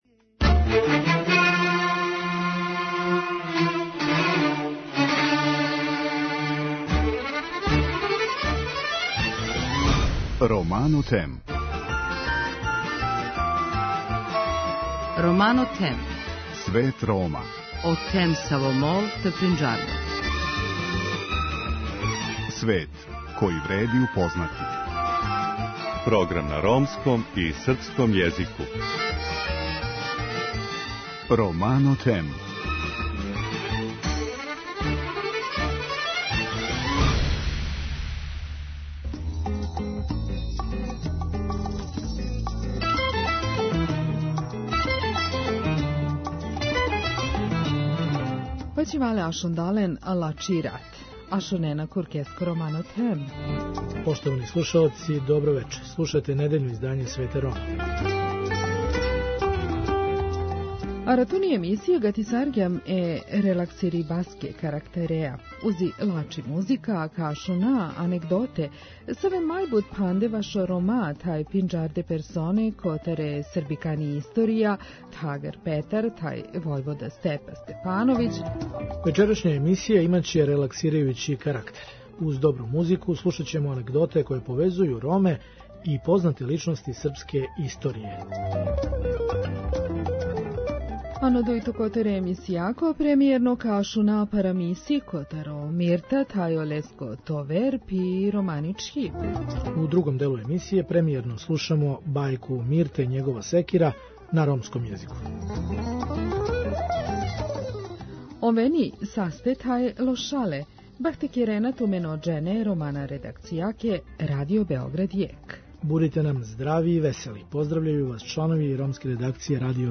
Уз добру музику, слушаћемо анегдоте које повезују Роме и познате личности српске историје: Милоша Обреновића, краља Петра I, војводу Степу Степановића... Чућете и анегдоту о атентатору на Адолфа Хитлера, Јохану Елзеру.